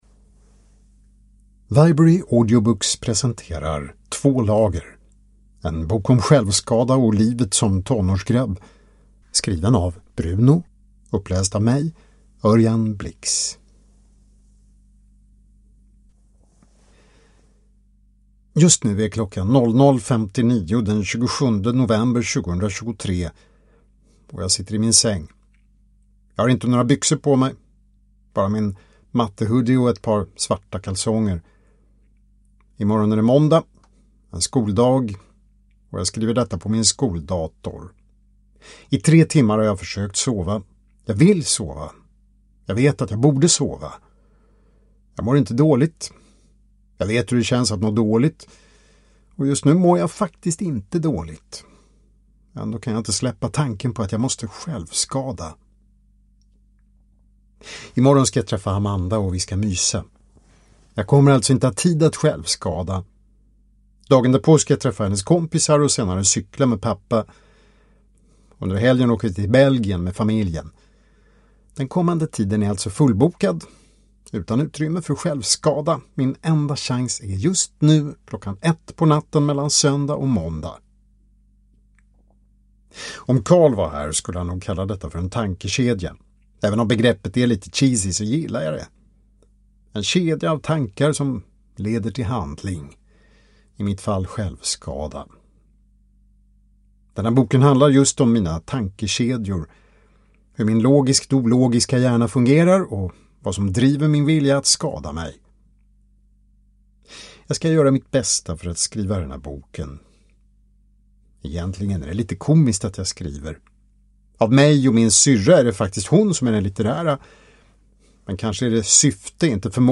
Två lager: En bok om självskada och livet som tonårsgrabb – Ljudbok